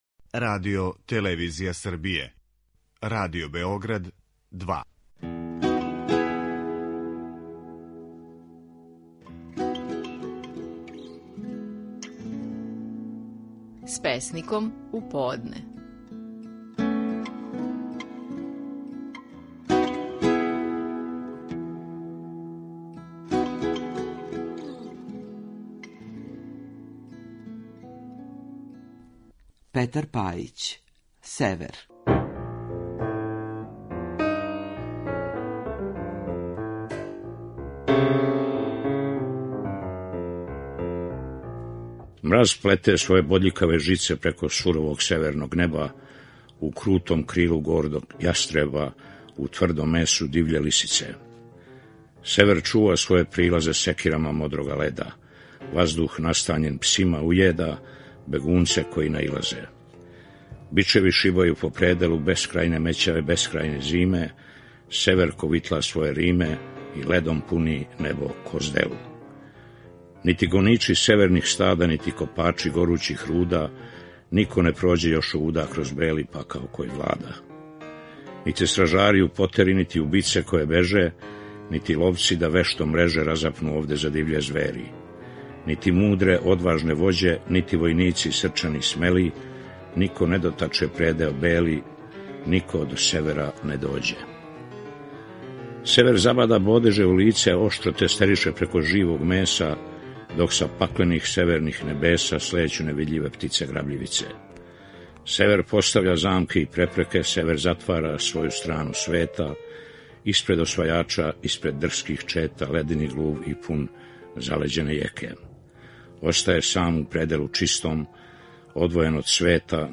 Стихови наших најпознатијих песника, у интерпретацији аутора.
Петар Пајић говори песму „Север".